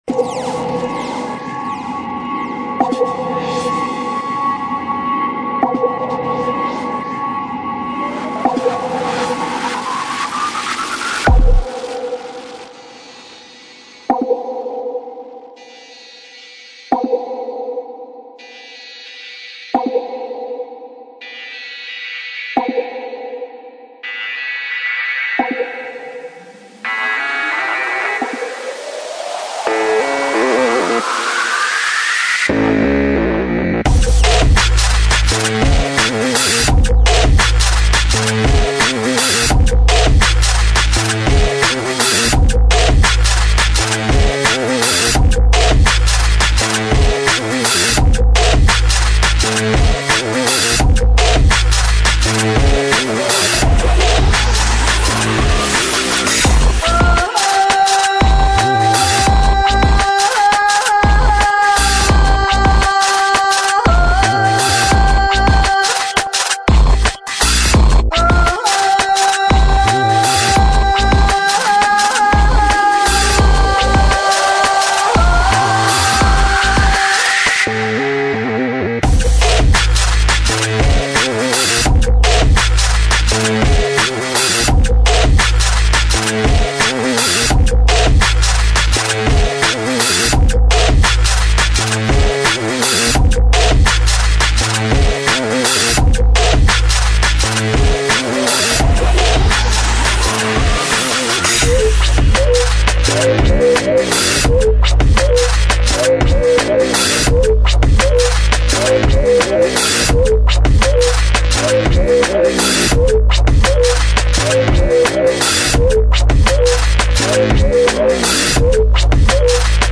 [ DRUM'N'BASS / BASS / HIP HOP ]